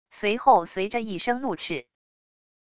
随后随着一声怒叱wav音频生成系统WAV Audio Player